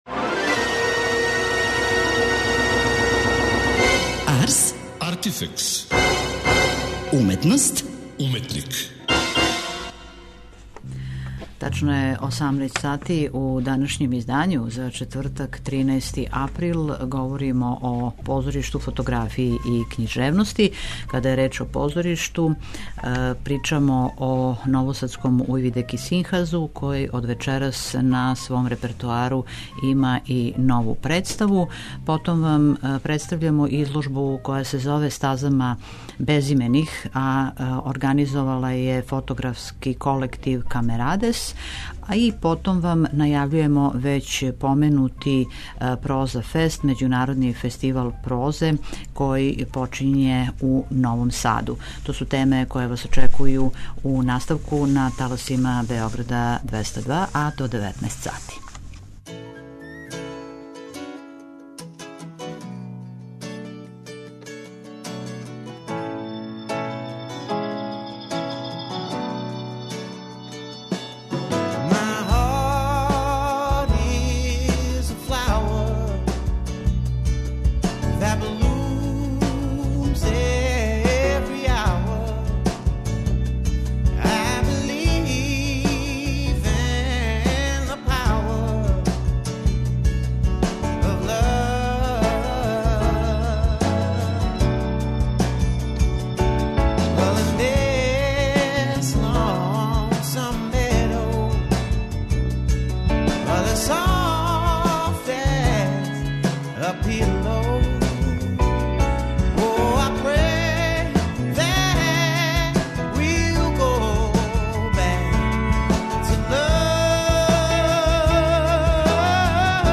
преузми : 27.72 MB Ars, Artifex Autor: Београд 202 Ars, artifex најављује, прати, коментарише ars/уметност и artifex/уметника.